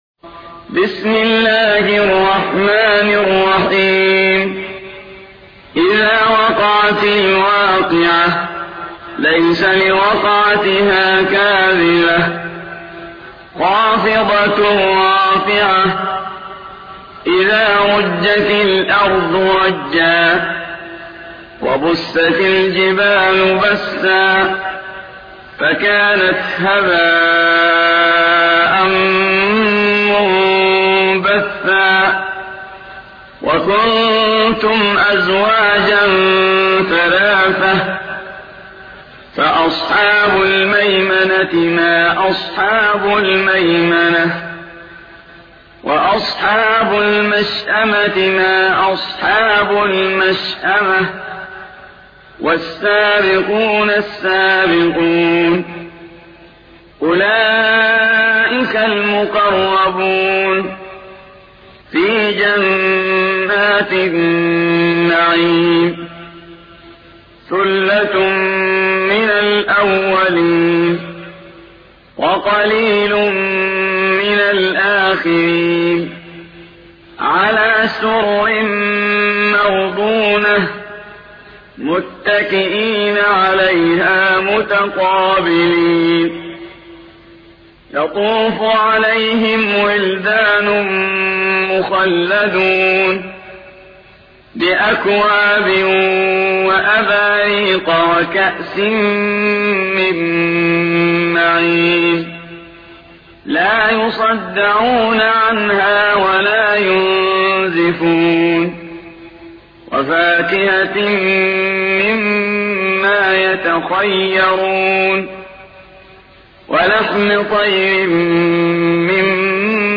56. سورة الواقعة / القارئ